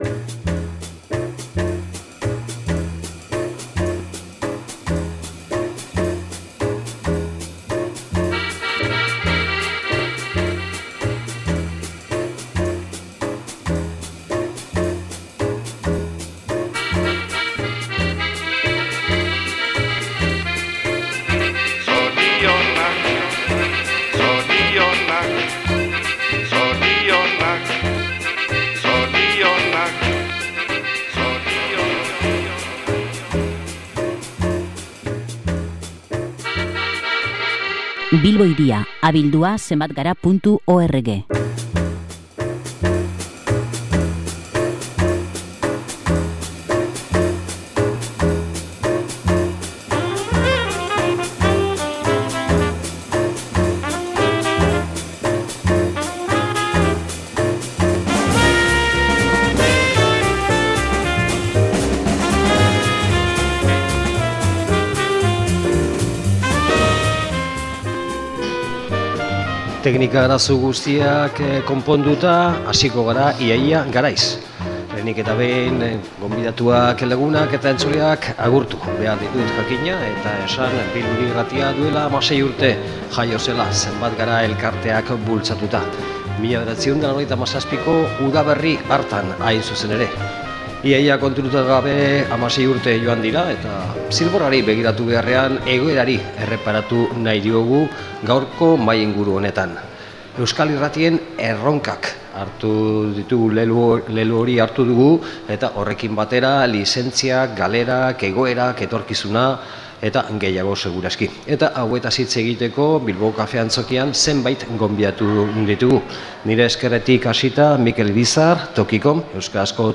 Info 7, Tas -Tas, Antxeta eta Bilbo Hiria irratiek, Berria egunkariak eta Tokikomek hartu dute parte Kafe Antzokian egindako mahai inguru honetan, Bilbo Hiria Irratiaren 16. urteurrena dela eta.
SOLASALDIA